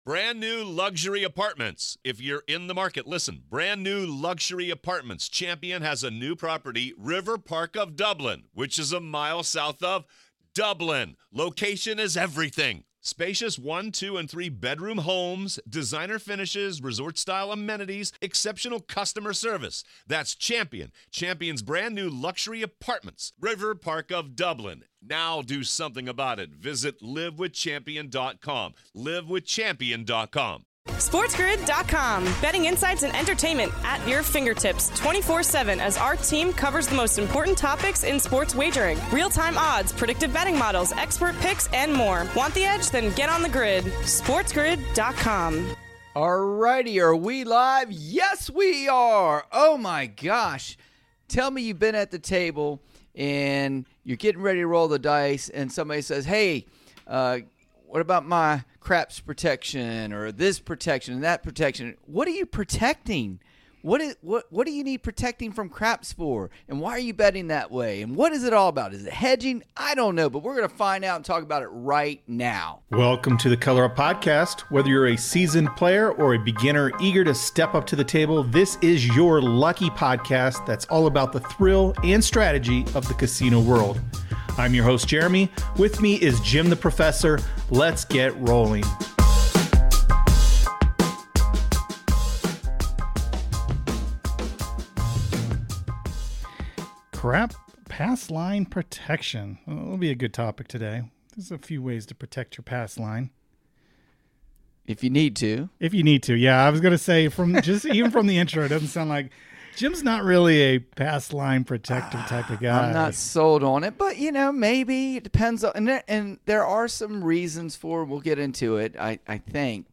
In this episode, the hosts engage in a thoughtful discussion about the necessity and strategies surrounding protecting the pass line bet in craps.